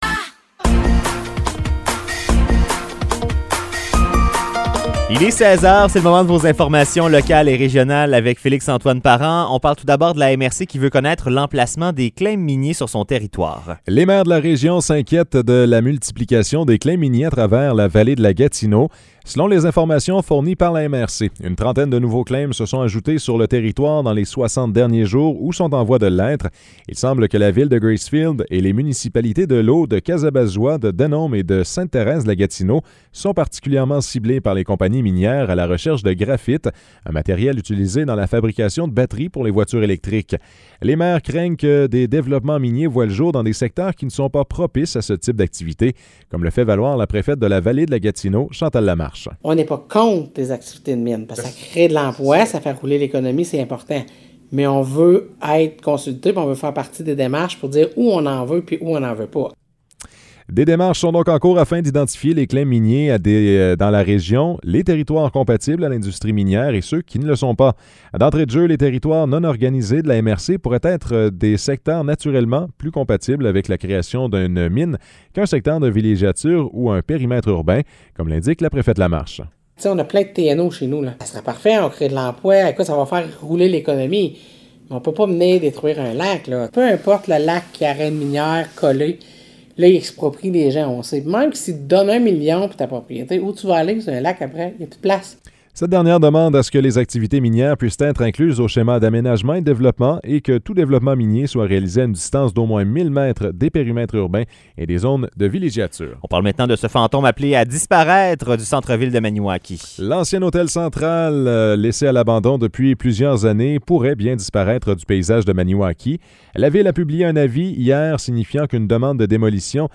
Nouvelles locales - 9 février 2023 - 16 h